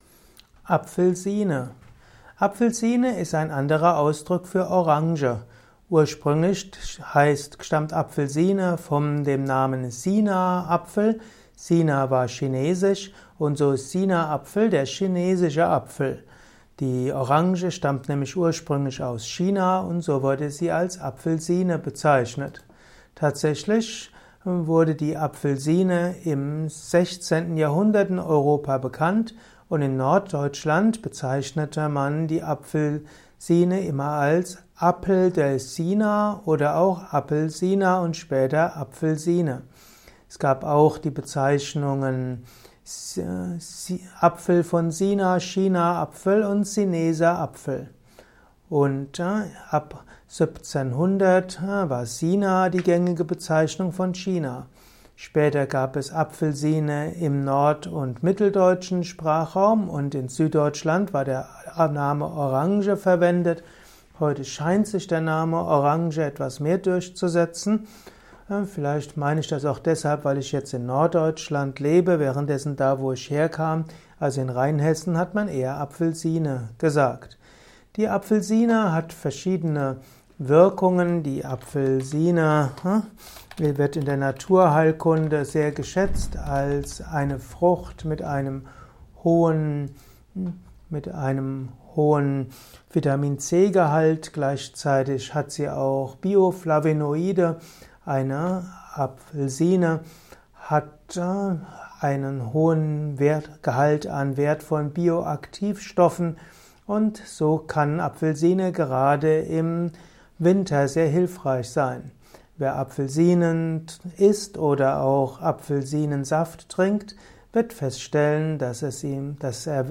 Audiovortrag zum Thema Apfelsine
Dieser Audiovortrag ist eine Ausgabe des Naturheilkunde Podcast.
Er ist ursprünglich aufgenommen als Diktat für einen